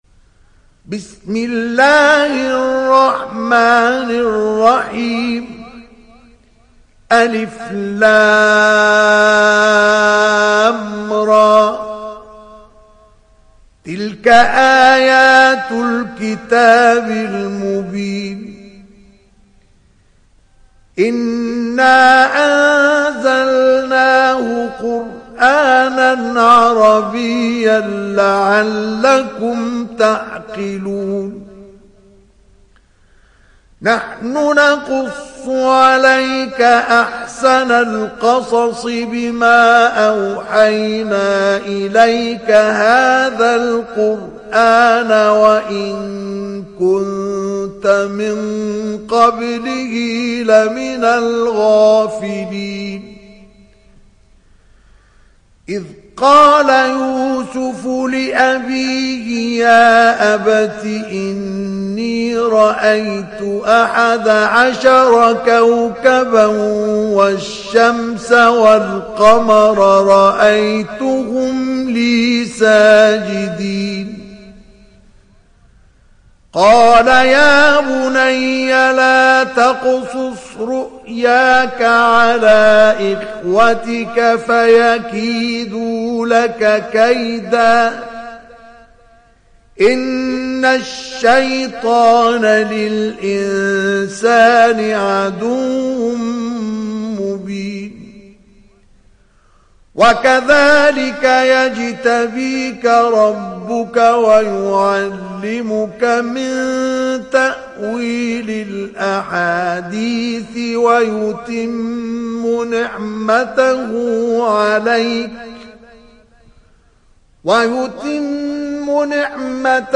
دانلود سوره يوسف mp3 مصطفى إسماعيل روایت حفص از عاصم, قرآن را دانلود کنید و گوش کن mp3 ، لینک مستقیم کامل